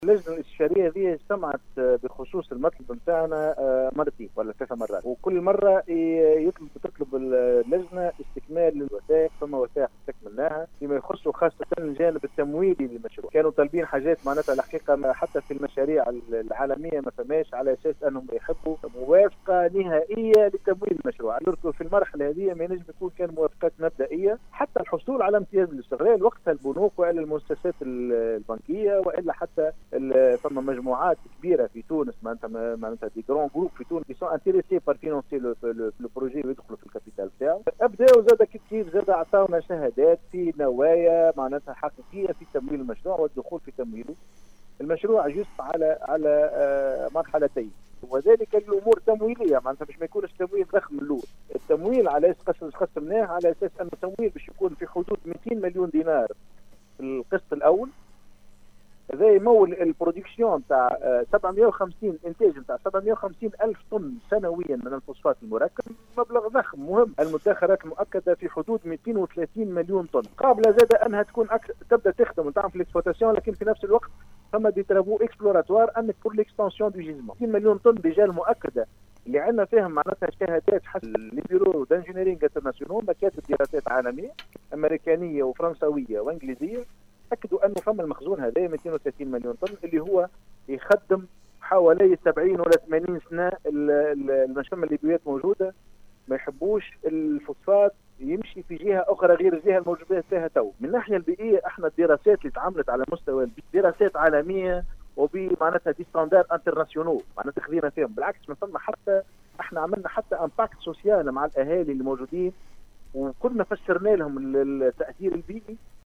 في تدخّل هاتفي صباح اليوم الجمعة 13 سبتمبر 2019 في برنامج compte rendu  بإذاعة السيليوم أف أم